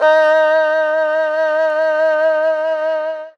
52-bi03-erhu-f-d#3.wav